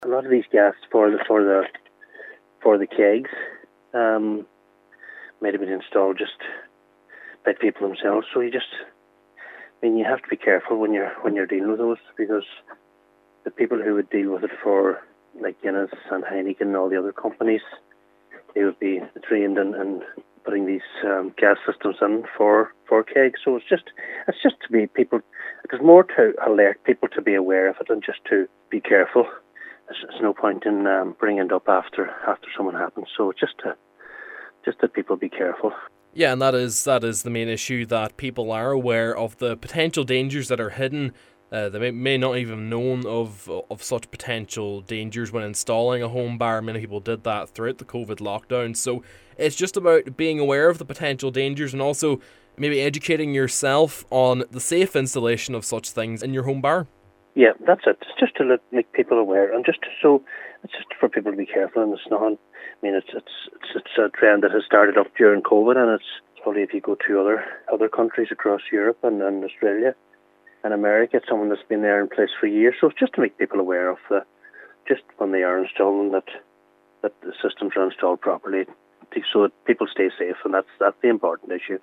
He says it’s important that people are aware of the risks of improperly installed gas canisters when setting up home bars: